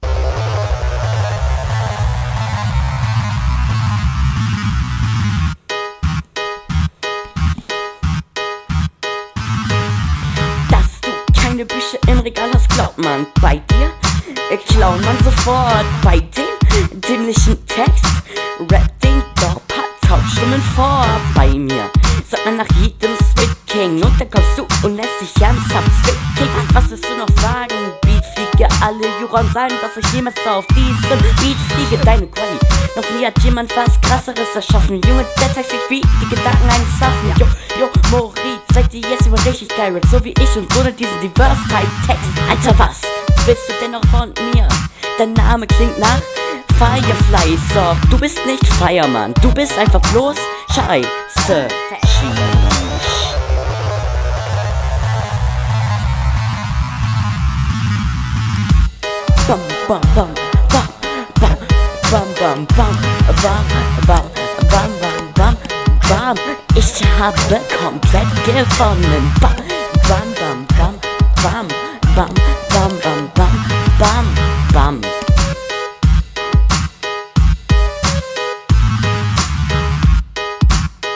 Ist die Stimme gepitcht oder bist du eine Frau?.